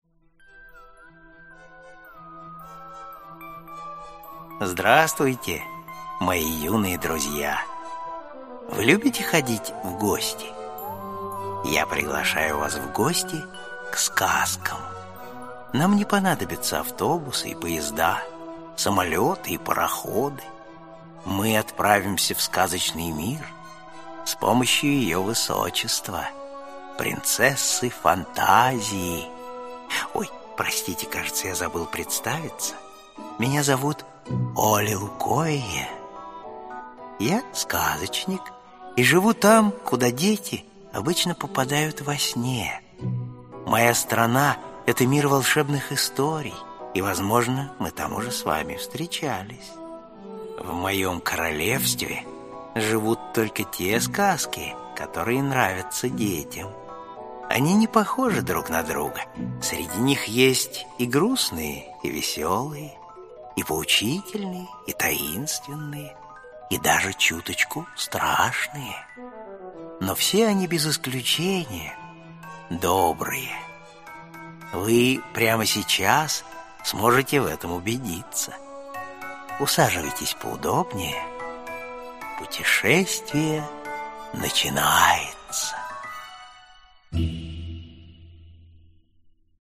Аудиокнига Синдбад-Мореход | Библиотека аудиокниг
Прослушать и бесплатно скачать фрагмент аудиокниги